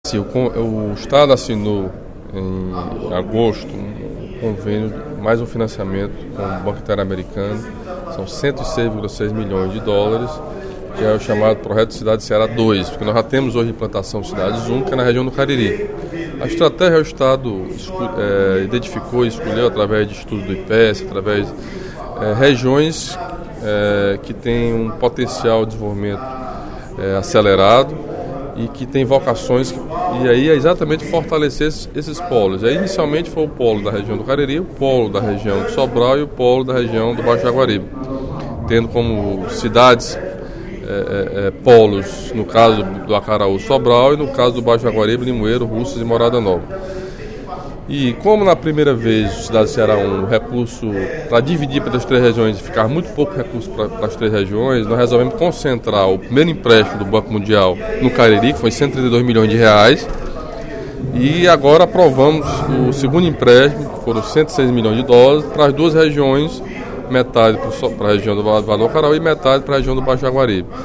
O Programa de Desenvolvimento Urbano de Polos Regionais, o Cidades do Ceará II, desenvolvido pelo Governo do Estado por meio da Secretaria das Cidades, foi enaltecido pelo deputado Camilo Santana (PT), em pronunciamento no primeiro expediente da sessão plenária desta terça-feira (03/12).
“Você só pode querer e imaginar que o cidadão permaneça no seu local se ele tiver o mínimo de dignidade”, considerou o deputado Osmar Baquit (PSD), em aparte ao pronunciamento de Camilo Santana.